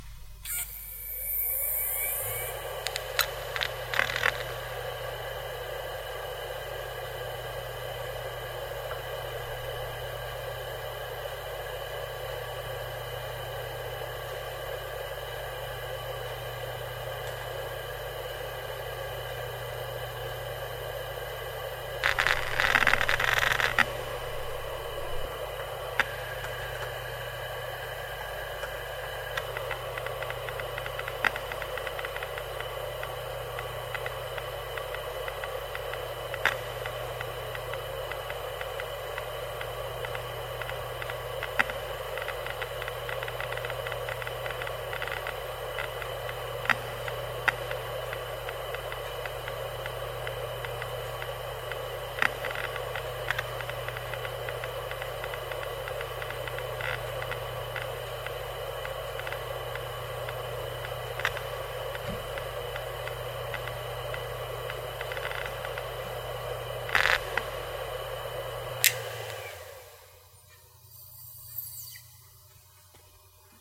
描述：2006年生产的日立硬盘近距离；旋转起来，又旋转下去。这个硬盘有3张盘片。(HDT725050VLAT80)
标签： 磁盘 马达 硬盘 驱动器 硬盘 嘎嘎
声道立体声